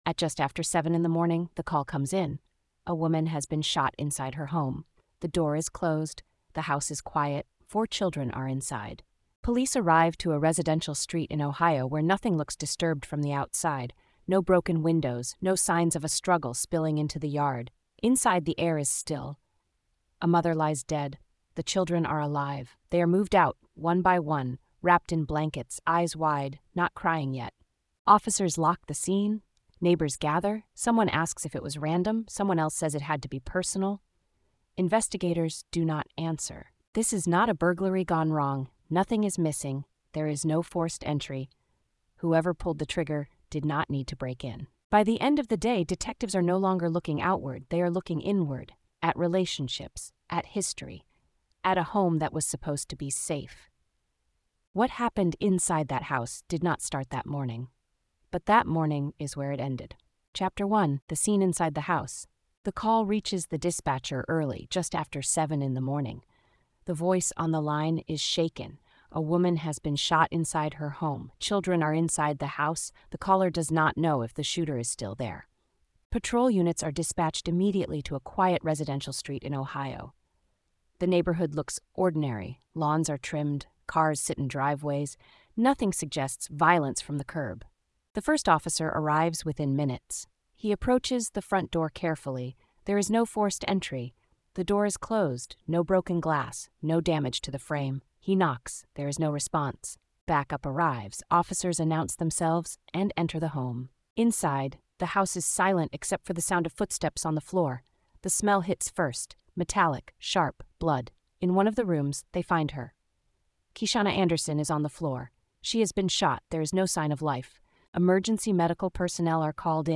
The story follows the investigation from the first police response through the tightening focus on domestic access and responsibility. Told in a neutral but gritty tone, it centers the facts, the timelines, and the pressure investigators faced while four children were left without their mother. The narrative avoids speculation, separates allegation from proof, and keeps dignity at the core.